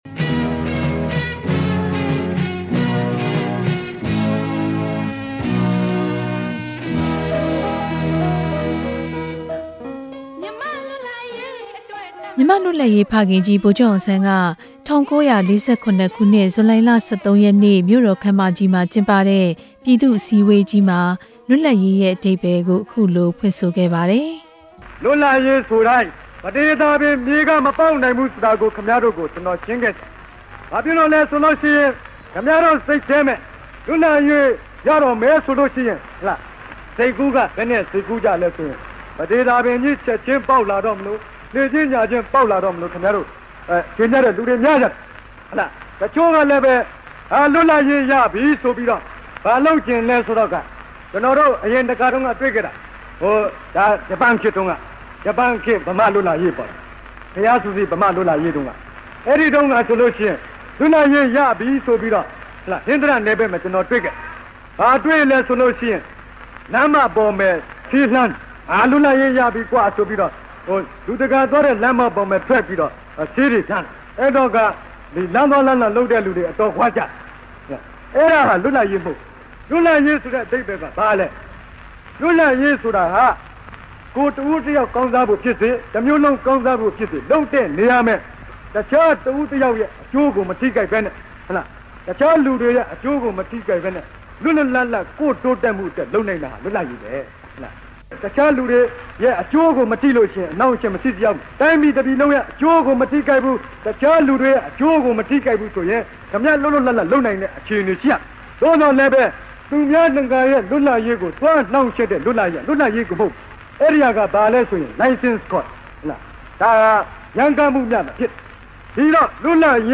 ဗိုလ်ချုပ်အောင်ဆန်း နောက်ဆုံးပြောကြားတဲ့ မိန့်ခွန်း